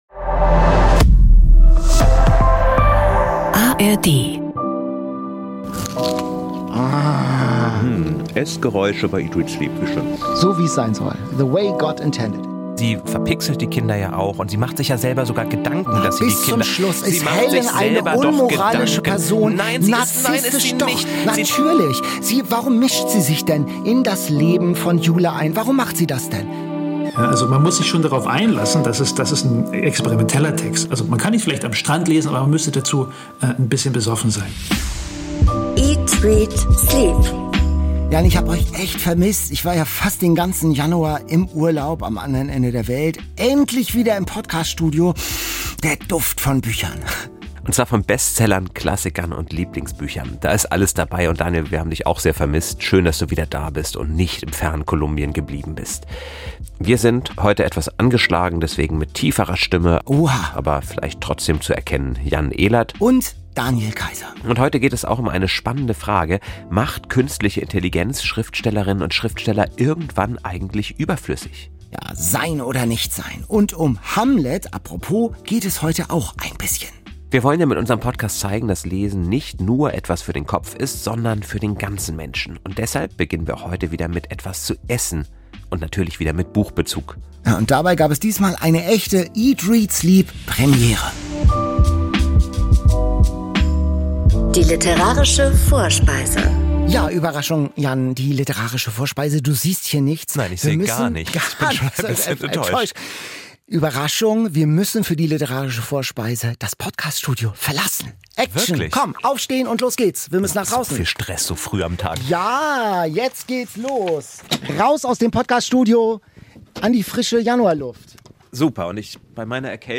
Der wiederum überrascht seinen Kollegen mit einem offenen Grill auf der Wiese vor dem NDR-Funkhaus.